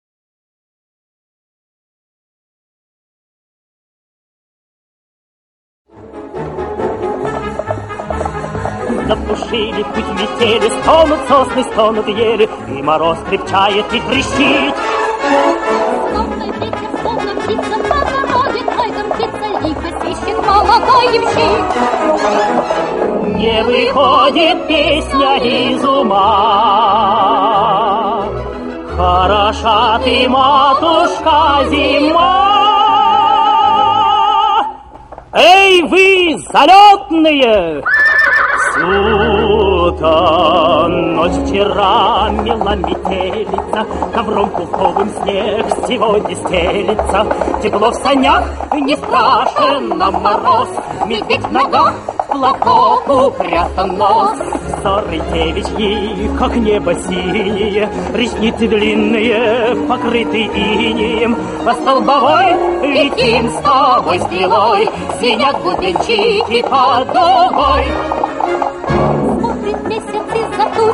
Певец (тенор).